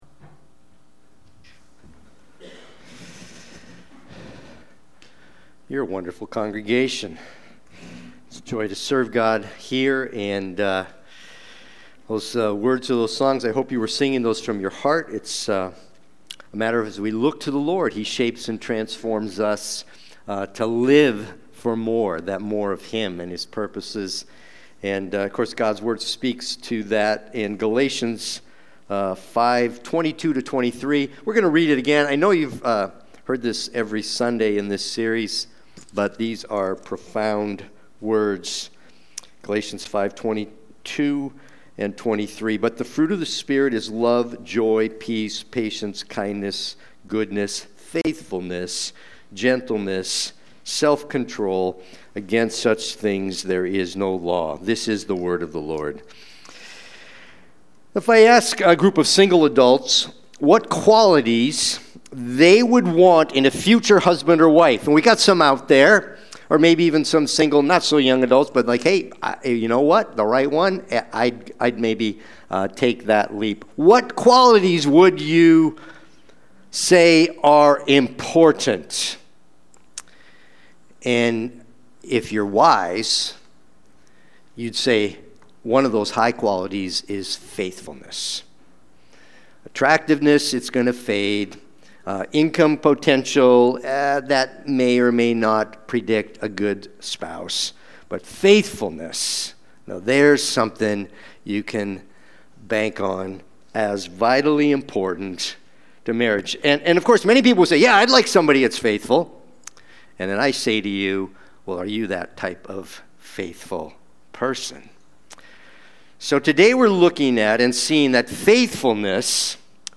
Sunday-Worship-main-8424.mp3